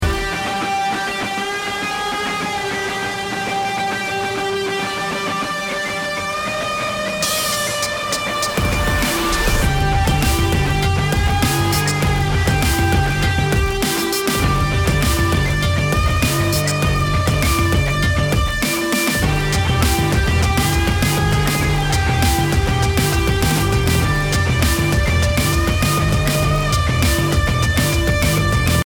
Royalty Free Music（無料音楽）
BPM 100